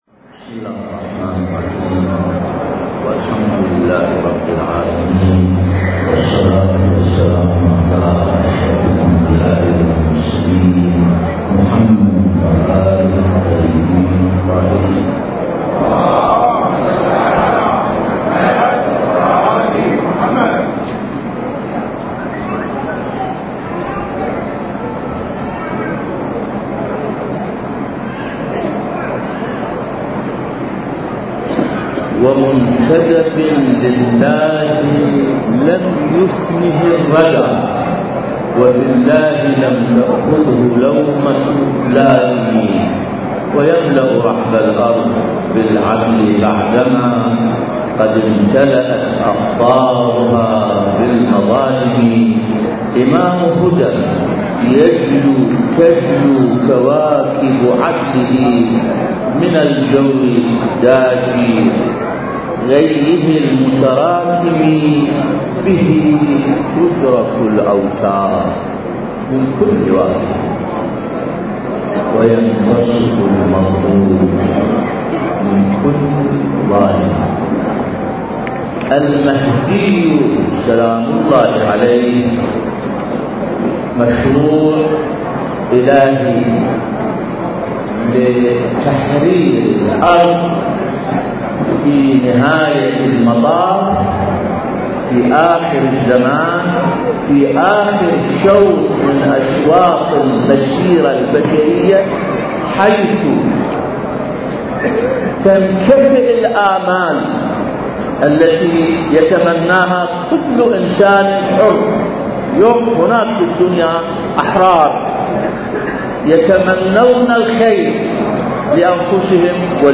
انتاج: مركز فجر عاشوراء الثقافي - العتبة الحسينية المقدسة المكان: مزار بكر بن علي - بابل الزمان: مهرجان الإمام المهدي (عجّل الله فرجه) الثقافي السنوي الرابع التاريخ: 1439 للهجرة